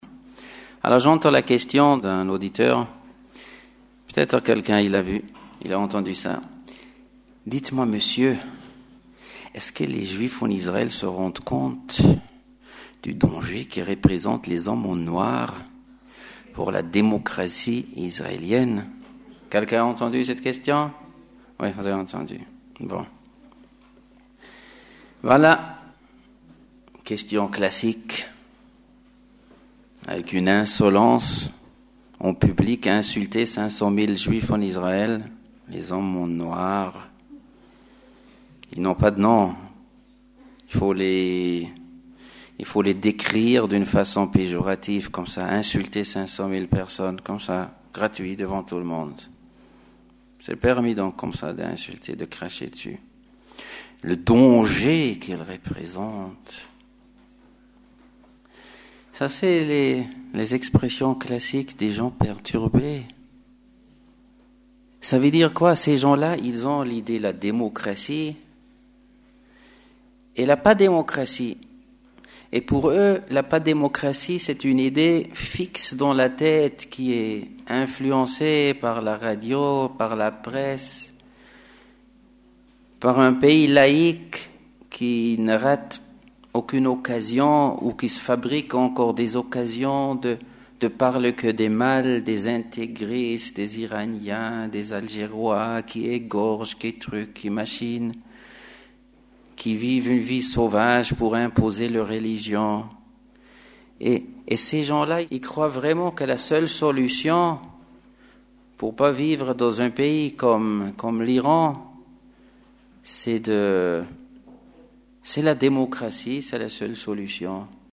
C’est uniquement pour que vous en retiriez le plus grand profit que nous y avons accordé tous nos soins en supprimant bruitages et ce qui pouvait en gêner l’audition.